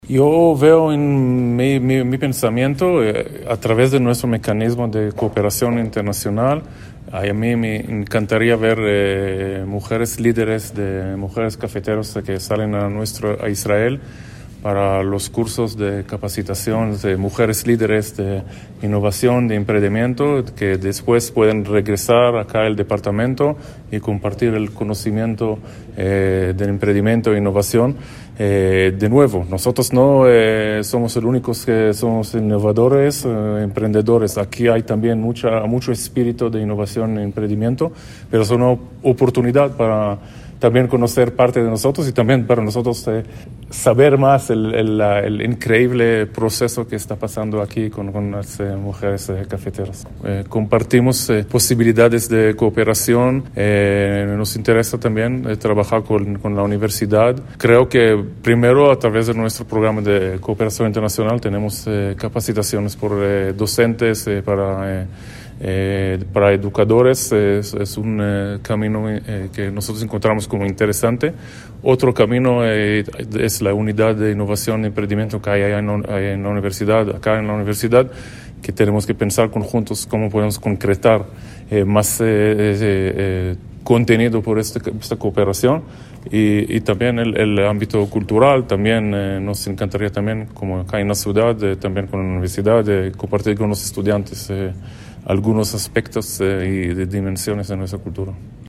Audio de: Gali Dagan, embajador de Israel – proyectos para el Quindío
Audio-embajador-israeli-Gali-Dagan-proyectos-para-el-Quindio.mp3